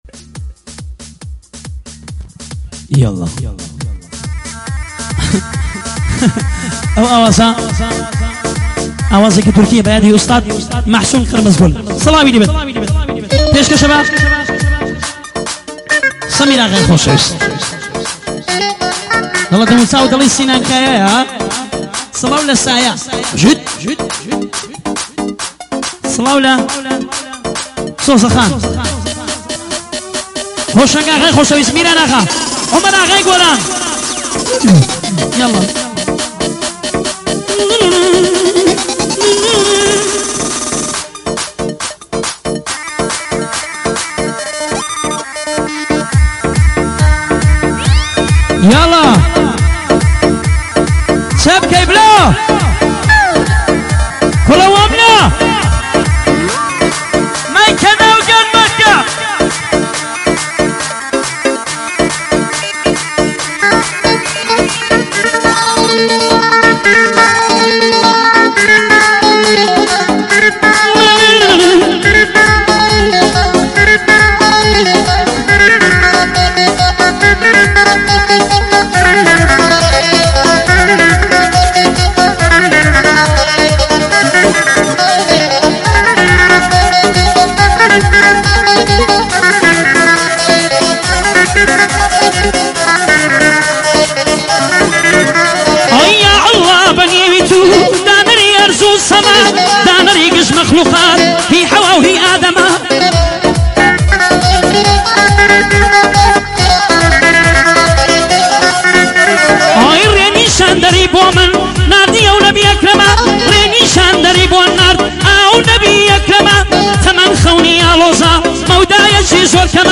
شوتی کردی بیس دار تند
شوتی کردی شوتی بازا